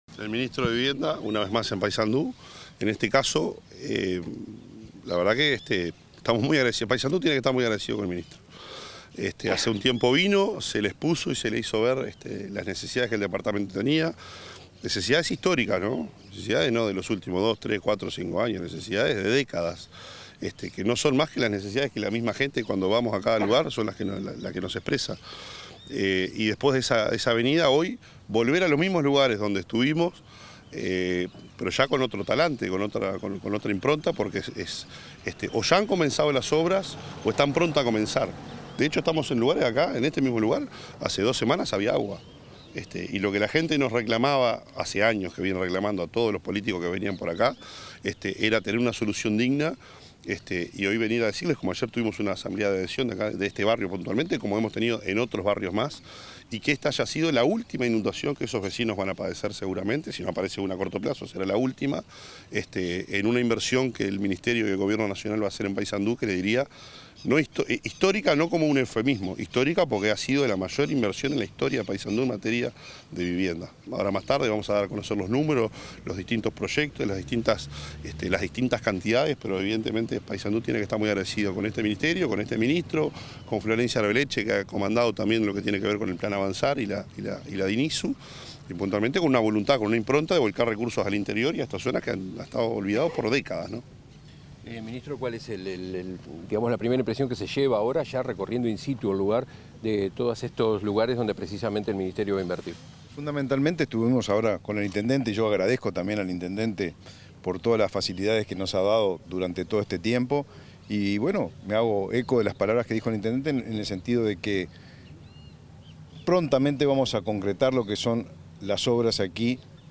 Declaraciones de autoridades del MVOT y el intendente de Paysandú
Declaraciones de autoridades del MVOT y el intendente de Paysandú 07/06/2024 Compartir Facebook X Copiar enlace WhatsApp LinkedIn Tras una recorrida por las obras del plan Avanzar en Paysandú, el titular del Ministerio de Vivienda y Ordenamiento Territorial (MVOT), Raúl Lozano; el intendente local, Nicolás Olivera, y la directora de Integración Social y Urbana de la referida secretaría de Estado, Florencia Arbeleche, dialogaron con la prensa.